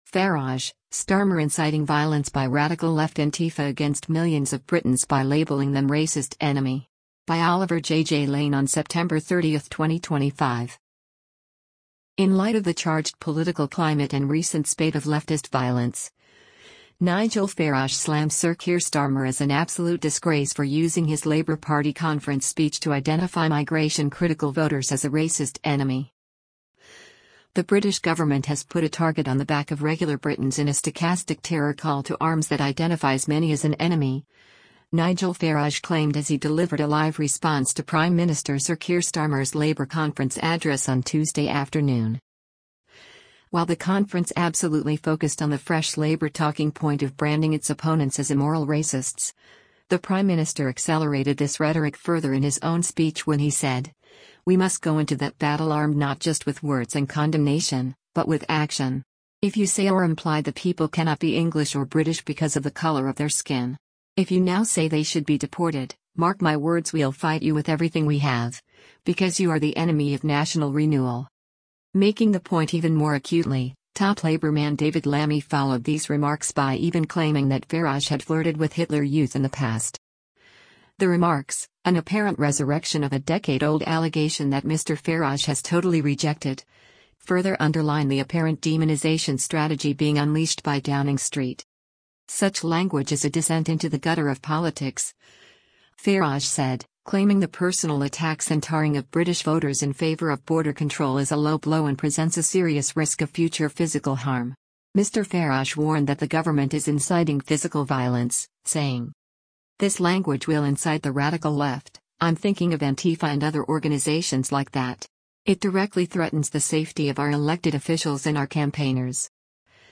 The British government has put a target on the back of regular Britons in a stochastic terror call-to-arms that identifies many as an “enemy”, Nigel Farage claimed as he delivered a live response to Prime Minister Sir Keir Starmer’s Labour conference address on Tuesday afternoon.